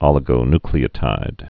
(ŏlĭ-gō-nklē-ə-tīd, -ny-, ōlĭ-)